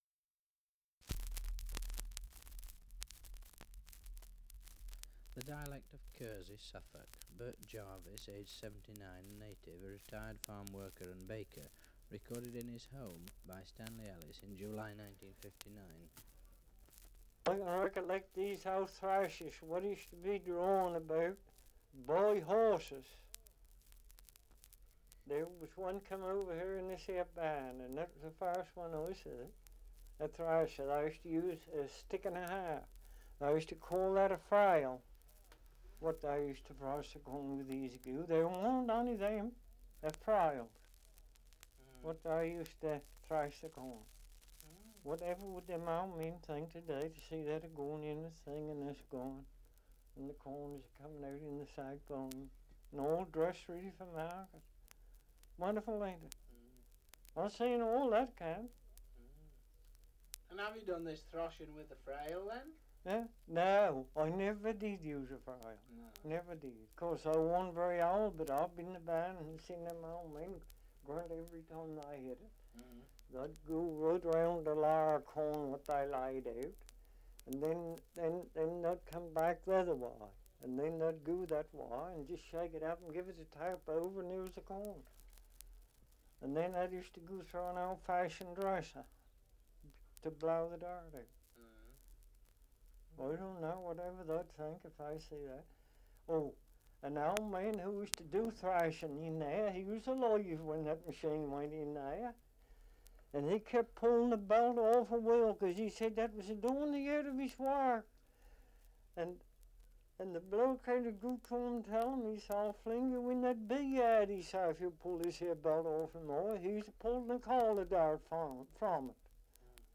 Survey of English Dialects recording in Kersey, Suffolk
78 r.p.m., cellulose nitrate on aluminium